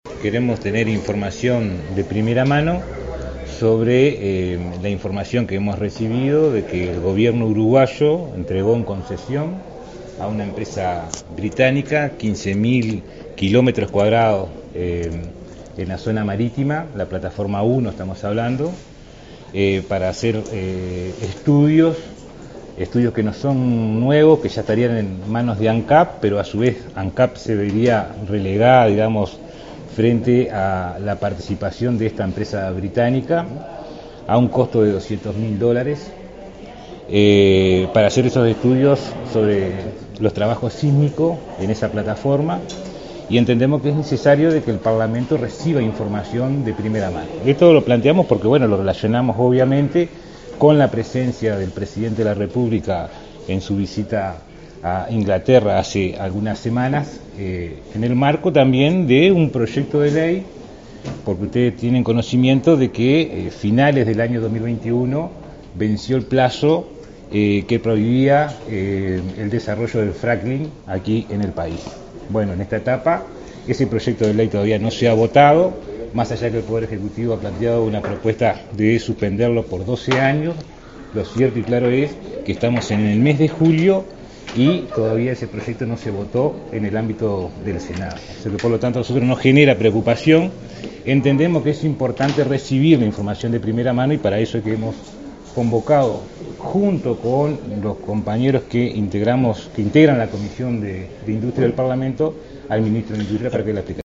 En ese sentido, explicó en rueda de prensa: “Lo relacionamos obviamente con la presencia del presidente de la República (Luis Lacalle Pou) en su visita a Inglaterra hace algunas semanas”.